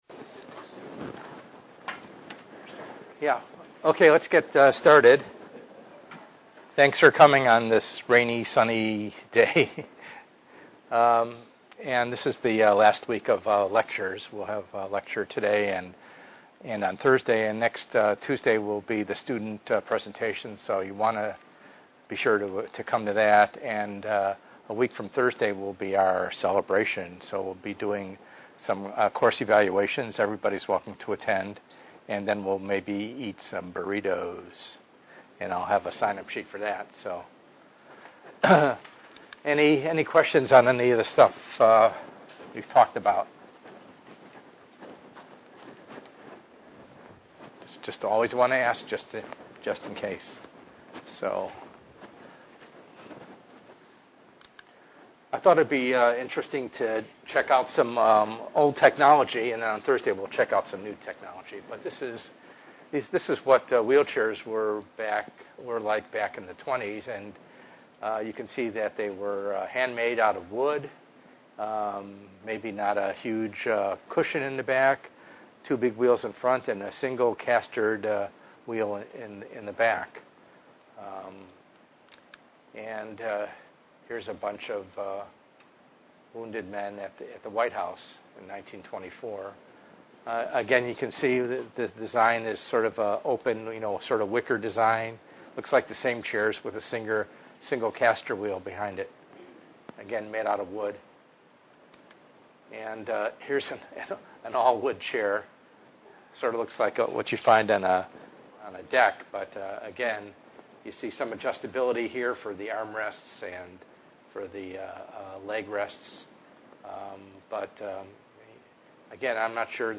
ENGR110/210: Perspectives in Assistive Technology - Lecture 9a